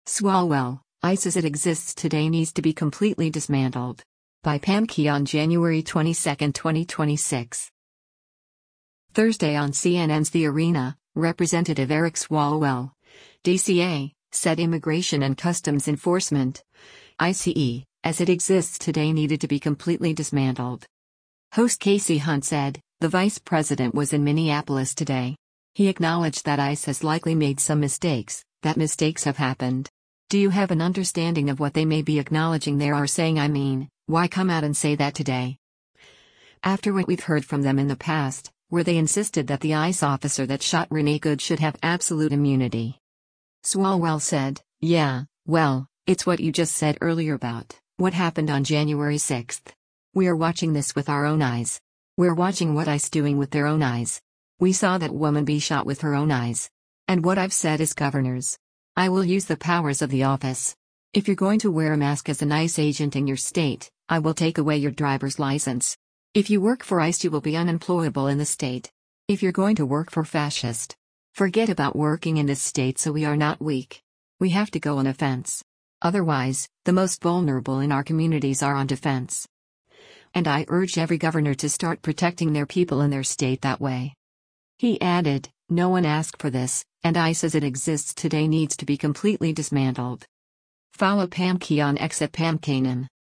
Thursday on CNN’s “The Arena,” Rep. Eric Swalwell (D-CA) said Immigration and Customs Enforcement (ICE) “as it exists today” needed to be completely dismantled.